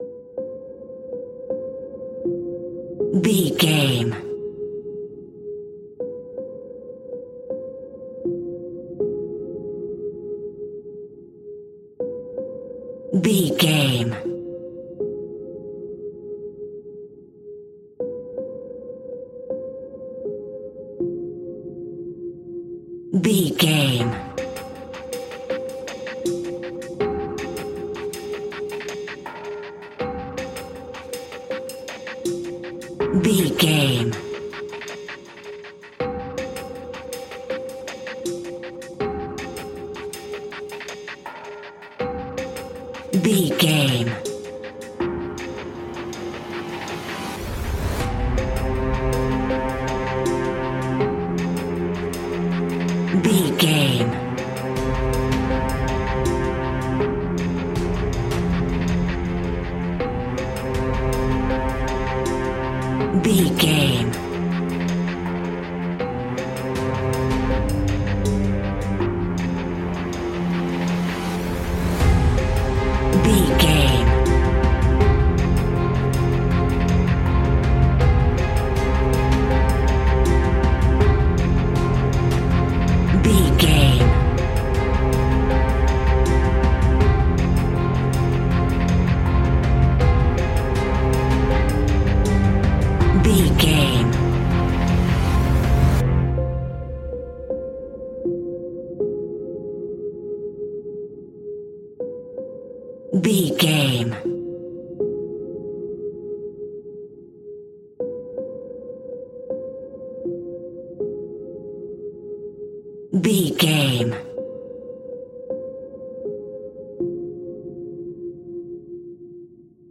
Aeolian/Minor
ominous
disturbing
eerie
strings
drums
percussion
synthesiser
electric piano
Horror Pads